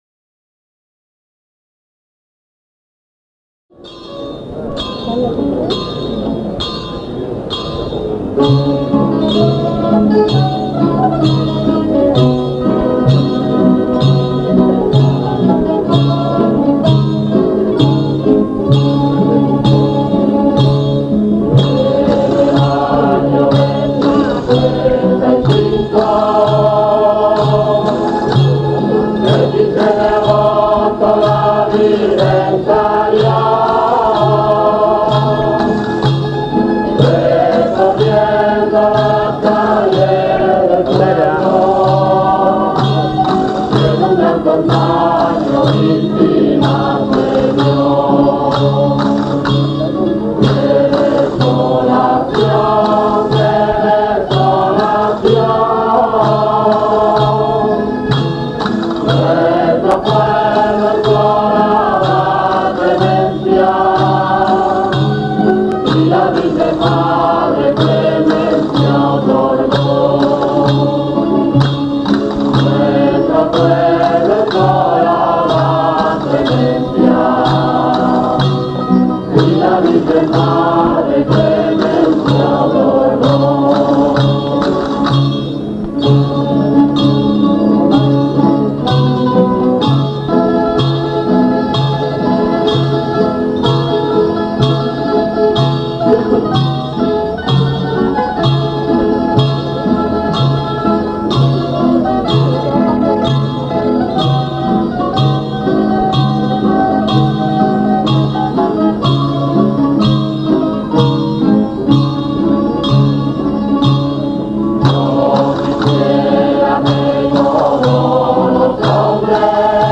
COPLAS DE LOS CAMPANILLEROS DEL VALLE